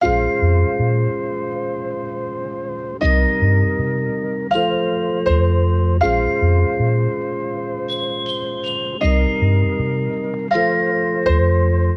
MELODY LOOPS
(160 BPM – Fm)